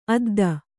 ♪ adda